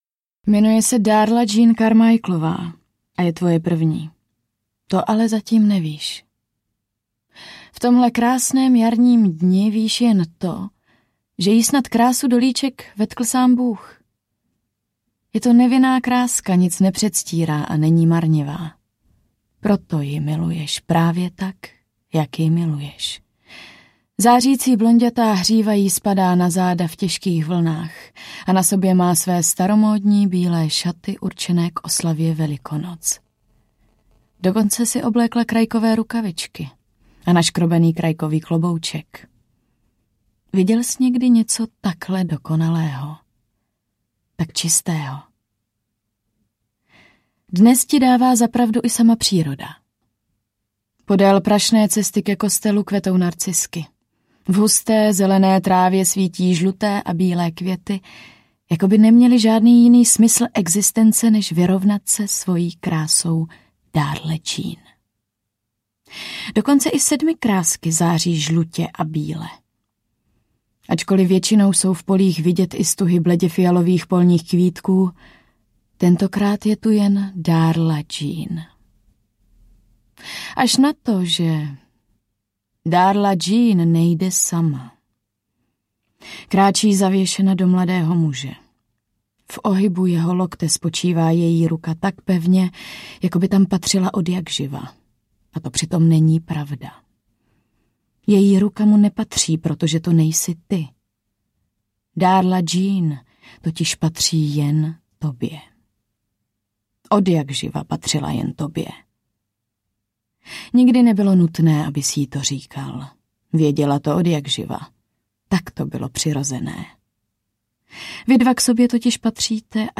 Májové růže audiokniha
Ukázka z knihy